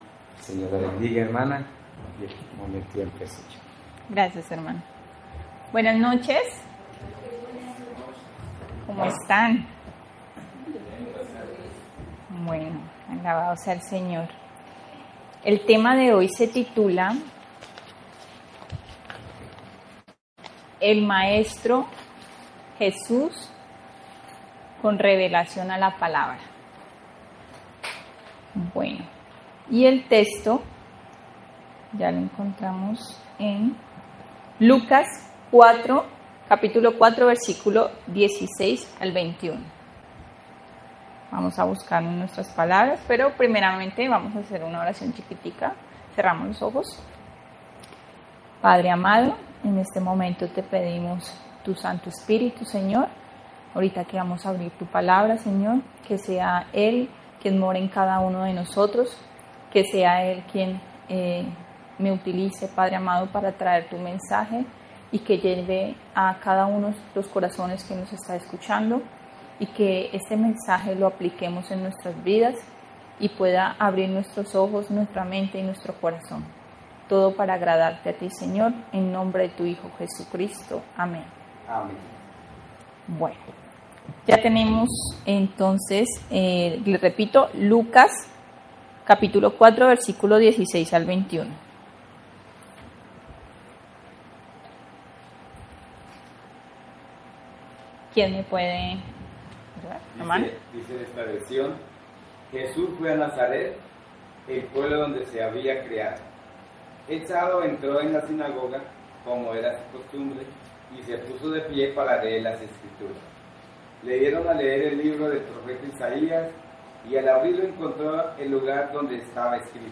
Semana de Oración Diciembre 2024